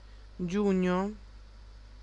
Ääntäminen
Ääntäminen France (Paris): IPA: [ʒy.ɛ̃] France (Avignon): IPA: /ʒɥɛ̃/ Tuntematon aksentti: IPA: /ʒy.œ̃/ Haettu sana löytyi näillä lähdekielillä: ranska Käännös Ääninäyte Substantiivit 1. giugno {m} Suku: m .